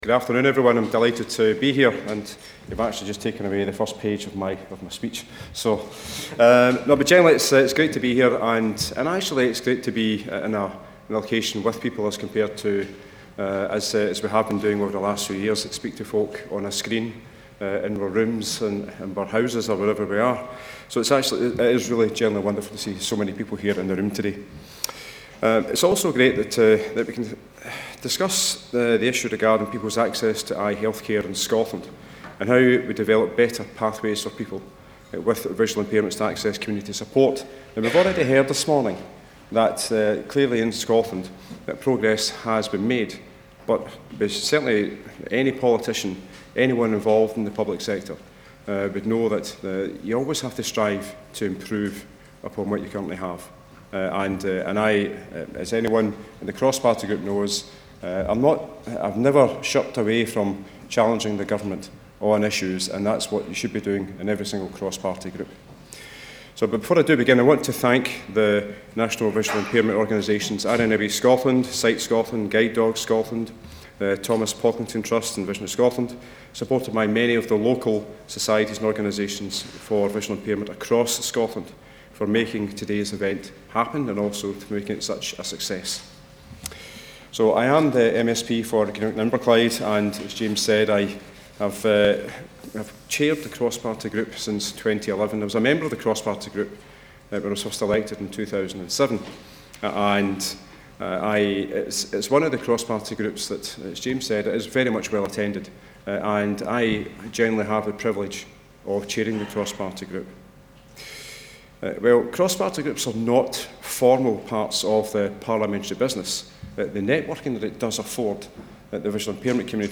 RNIB Connect - Scottish Vision Strategy Conference 2023 - Stuart McMillan MSP